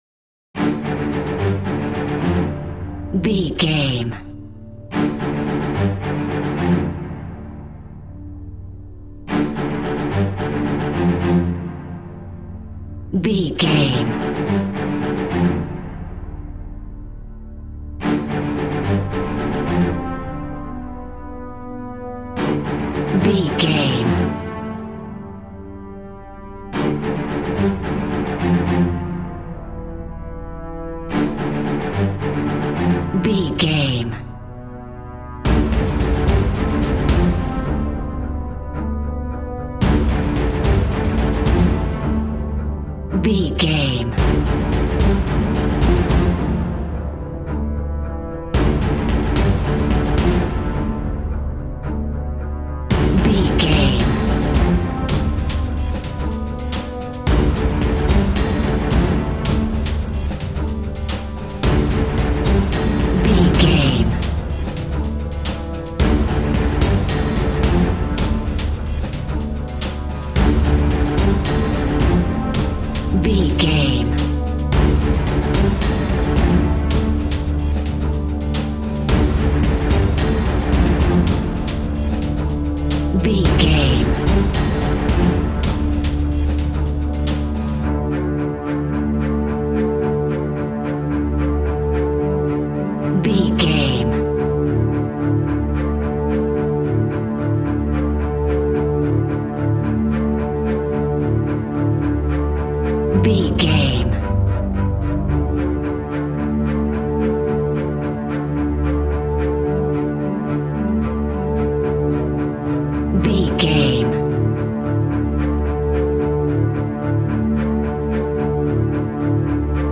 Epic / Action
Fast paced
In-crescendo
Uplifting
Ionian/Major
strings
brass
percussion
synthesiser